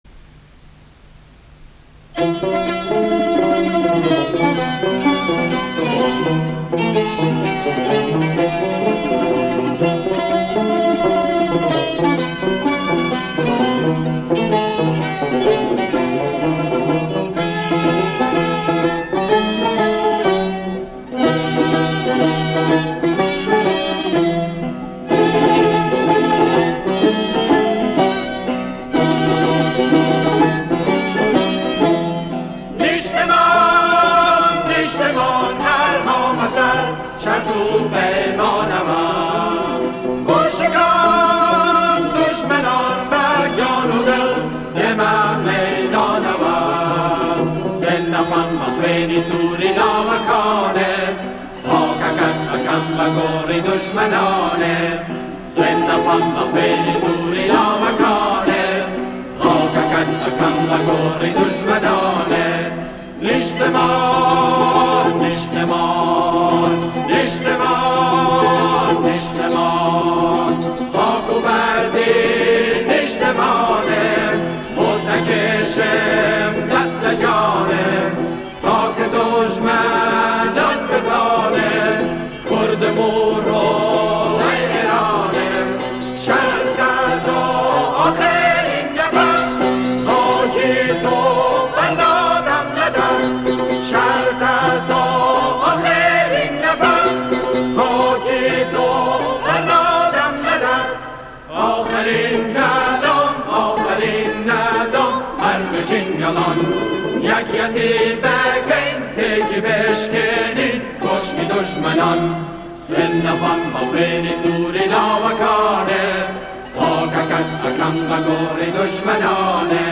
برای گروه کر